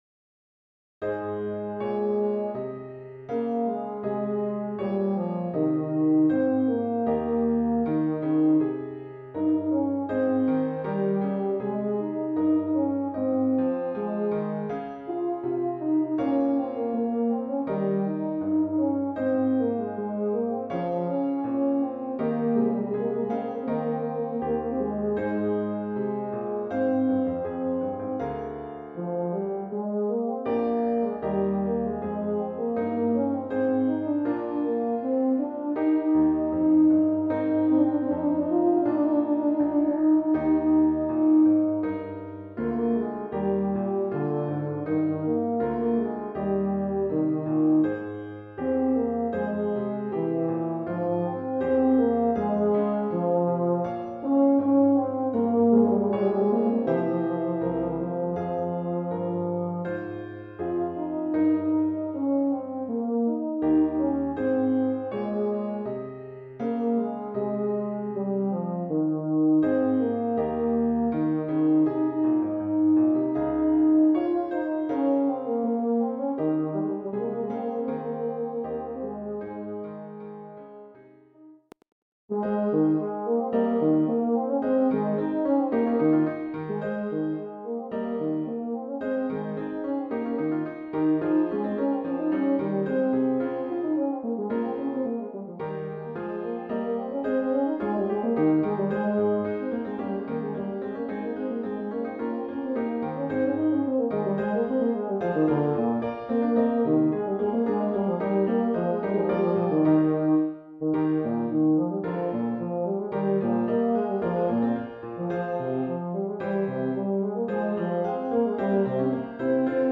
Voicing: EuphoniumSolo